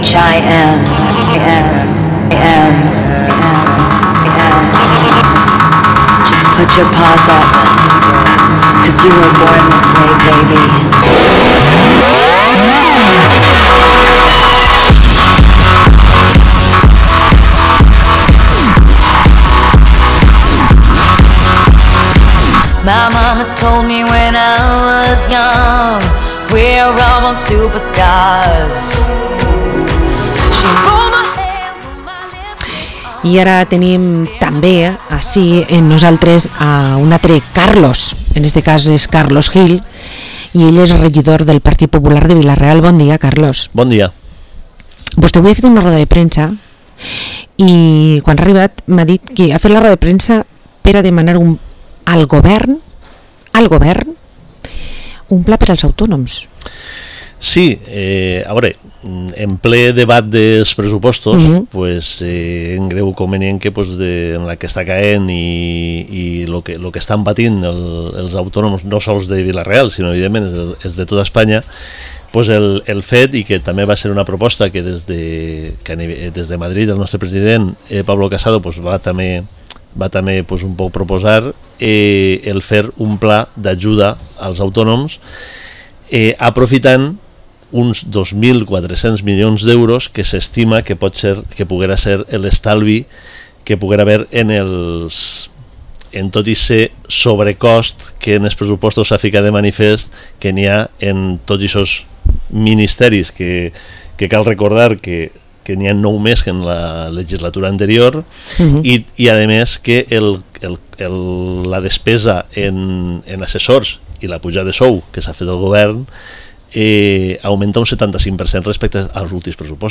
Entrevista al concejal del PP de Vila-real, Carlos Gil